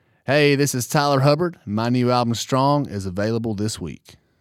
LINER Tyler Hubbard (available this week)